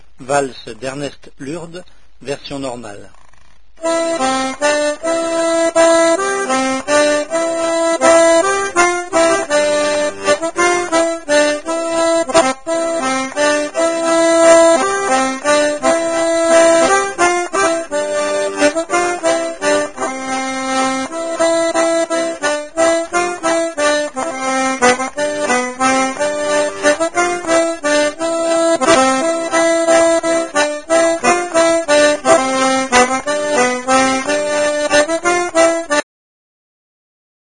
initiation diato français